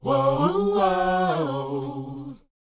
WHOA C#AD.wav